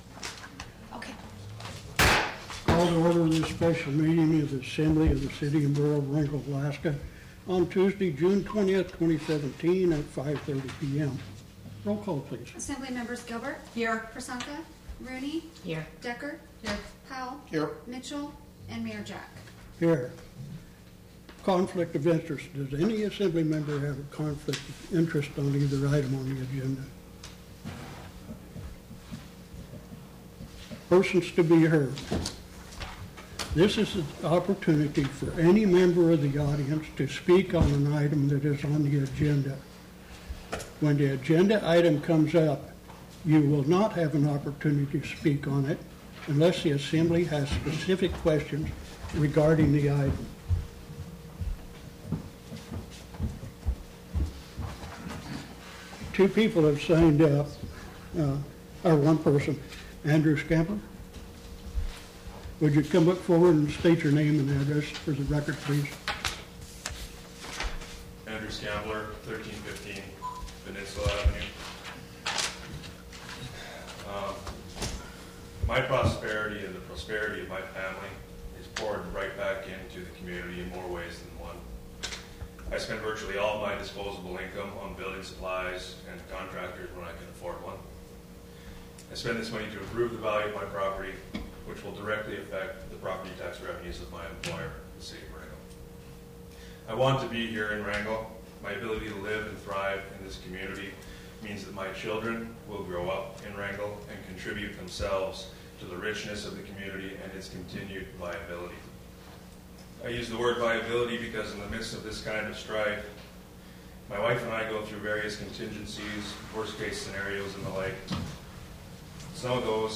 Special Assembly Meeting June 20, 2017 - KSTK
Tuesday, June 20, 2017 Location: Assembly Chambers, City Hall